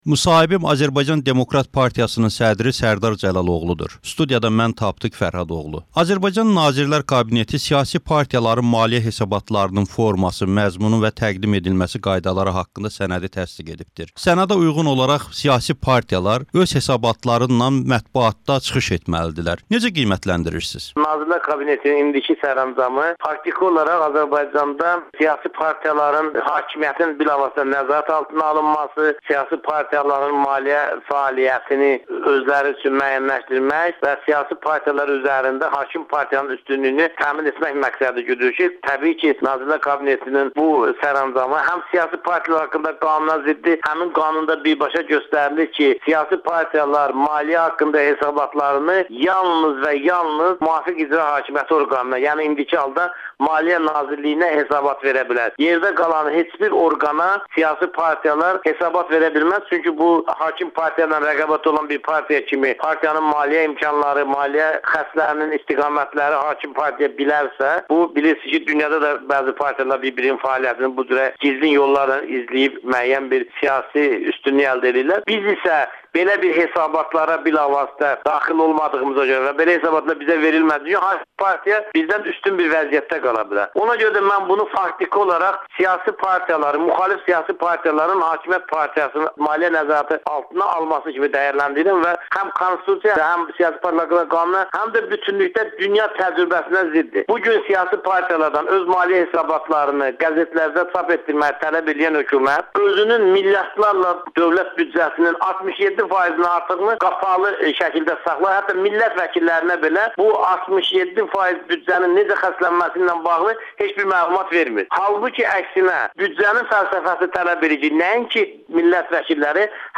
müsahibə